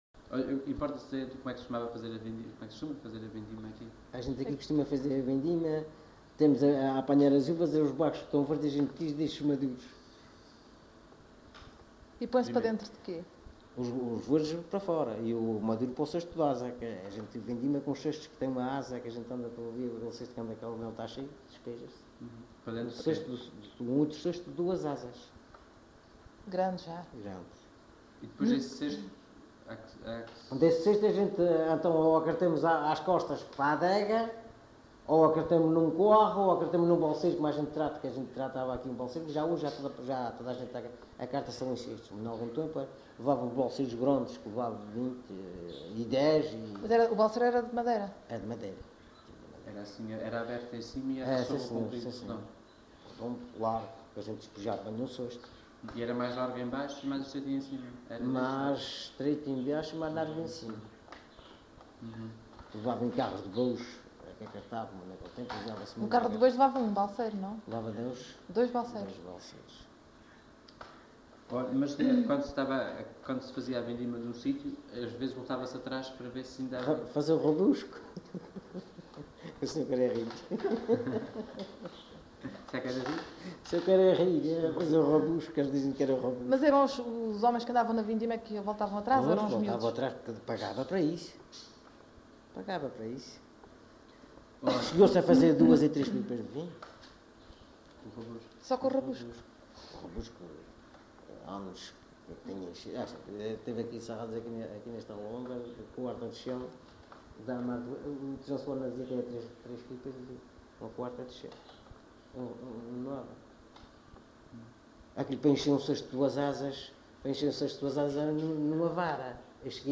LocalidadeBiscoitos (Angra do Heroísmo, Angra do Heroísmo)